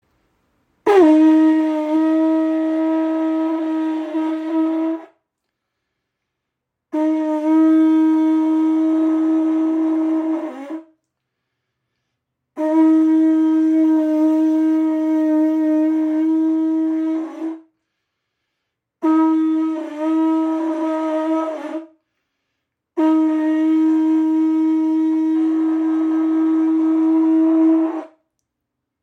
Muschelhorn | Pūtātara | Shankha | Conch Shell | Kavadi | Conque | ca. 24 cm